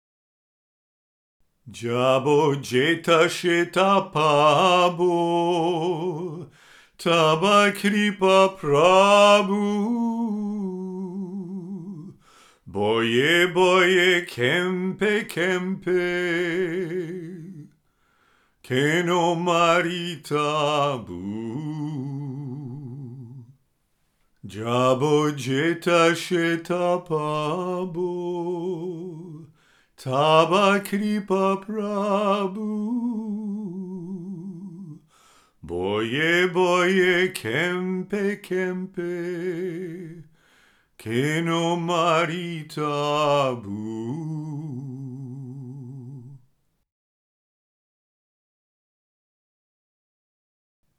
Music for meditation and relaxation.